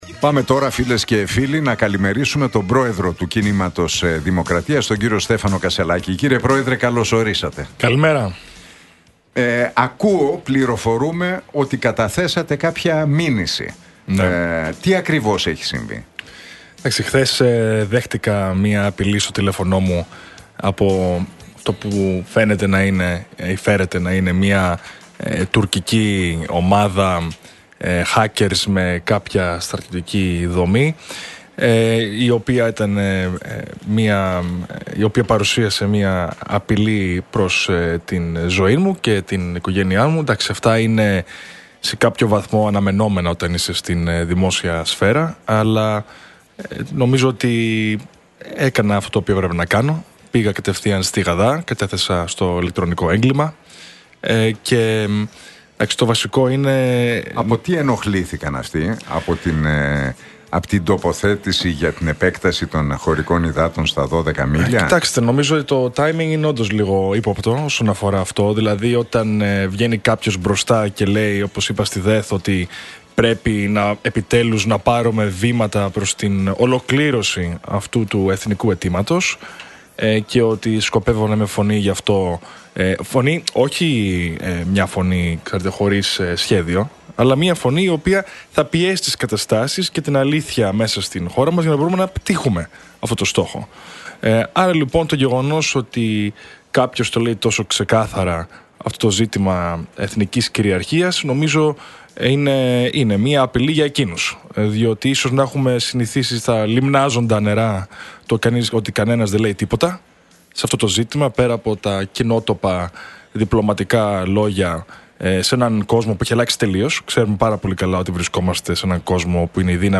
Για το πρόγραμμα που παρουσίασε στην ΔΕΘ, τις πολιτικές εξελίξεις και την μήνυση που κατέθεσε μίλησε ο πρόεδρος του Κινήματος Δημοκρατίας, Στέφανος Κασσελάκης στον Νίκο Χατζηνικολάου από την συχνότητα του Realfm 97,8.